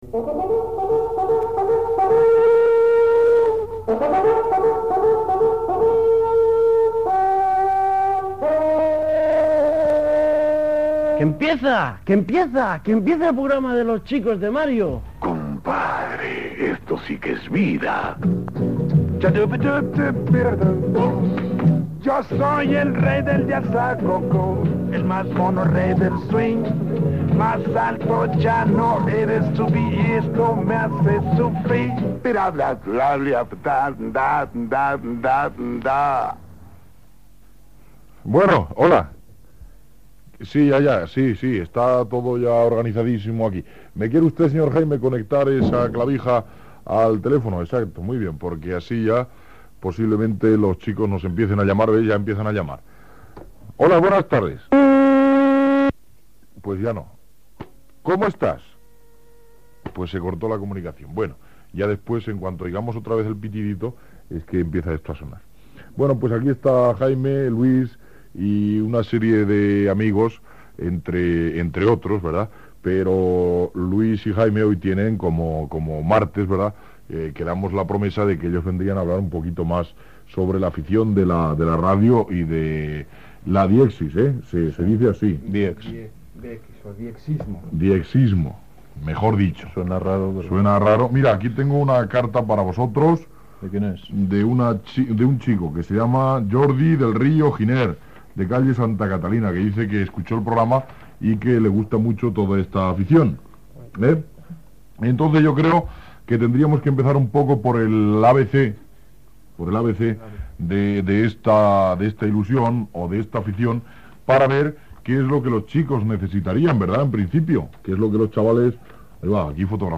trucada telefònica, tema musical, trucada telefònica, hora, últimes notícies
Gènere radiofònic Infantil-juvenil